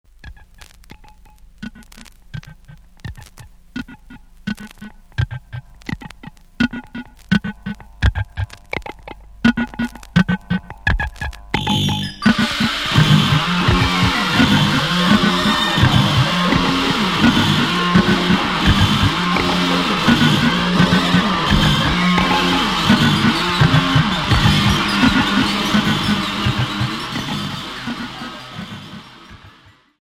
Punk industriel